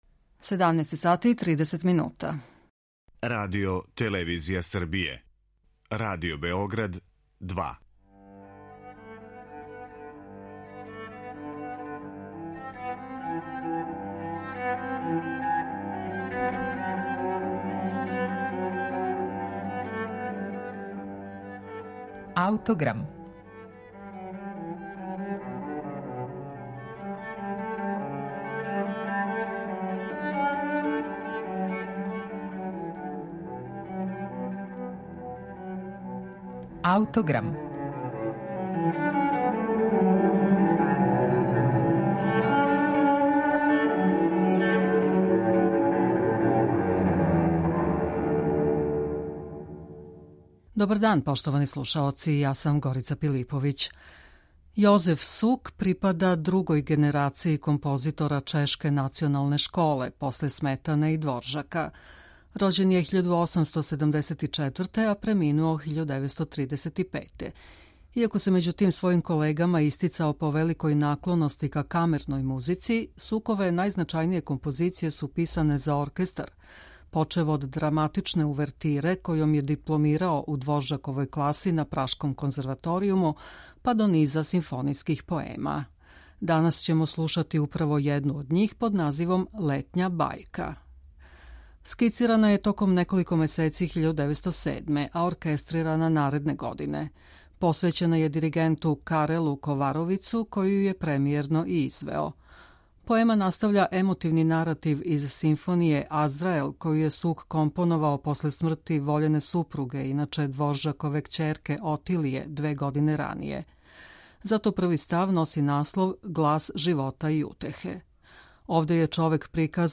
Најзначајнија дела чешког композитора Јозефа Сука писана су за оркестар.